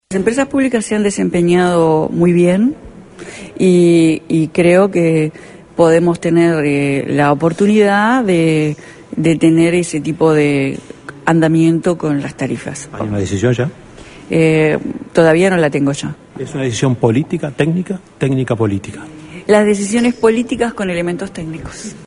Consultada en rueda de prensa, la ministra Cosse dijo que esa medida está a estudio: